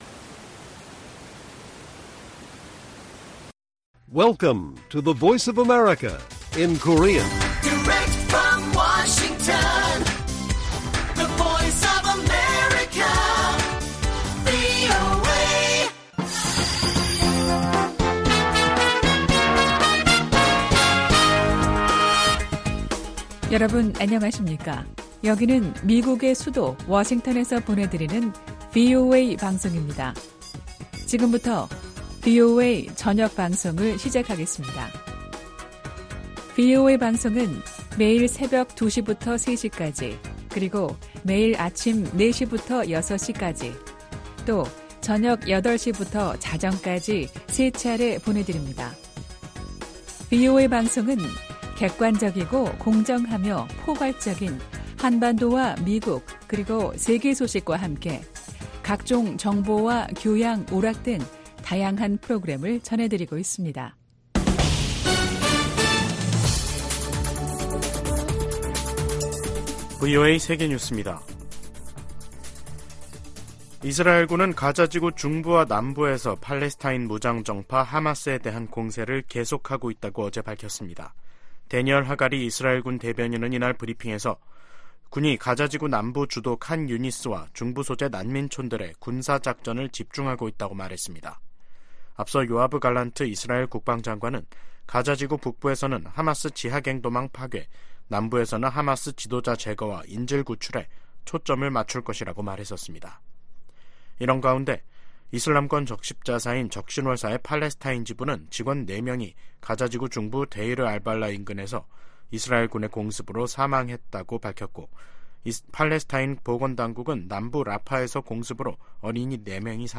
VOA 한국어 간판 뉴스 프로그램 '뉴스 투데이', 2024년 1월 11일 1부 방송입니다. 백악관은 팔레스타인 무장정파 하마스가 북한 무기를 사용한 사실을 인지하고 있다고 밝혔습니다. 미국, 한국, 일본 등이 유엔 안보리 회의에서 러시아가 북한에서 조달한 미사일로 우크라이나를 공격하고 있는 것을 강력하게 비판했습니다. 미국은 중국과의 올해 첫 국방 정책 회담에서 북한의 최근 도발에 우려를 표명하고 철통 같은 인도태평양 방위 공약을 재확인했습니다.